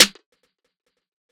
Snare [ 888 ].wav